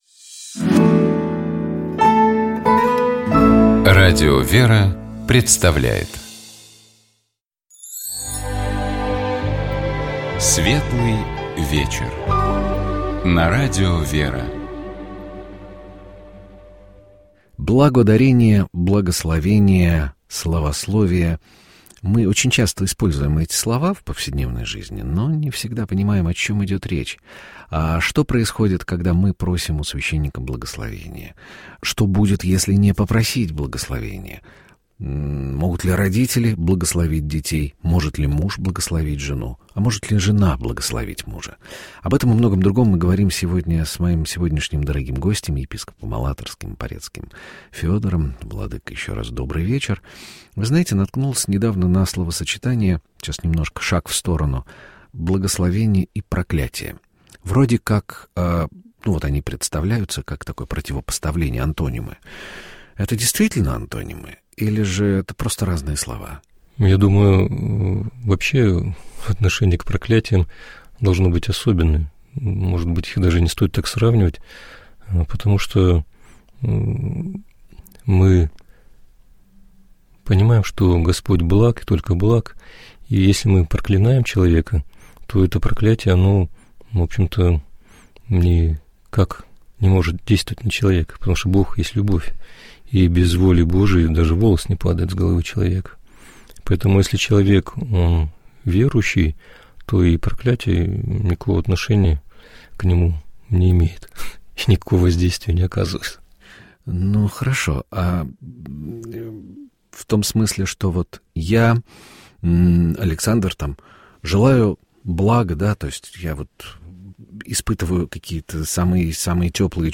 У нас в гостях был епископ Алатырский и Порецкий Феодор.